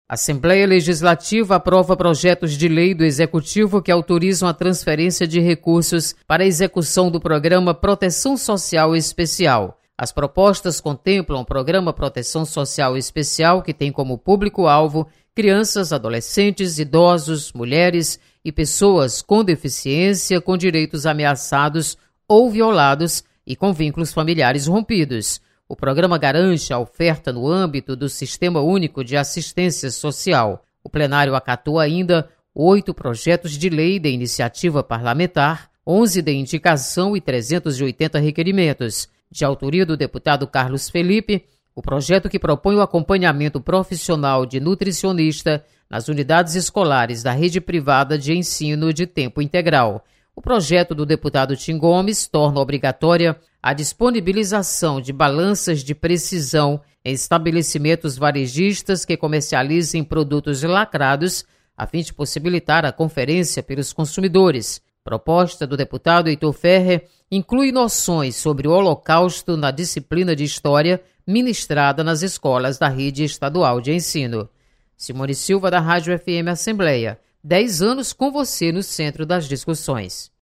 Aprovada mensagem do Governo do estado que transfere recursos para Programa de Proteção Social Especial. Repórter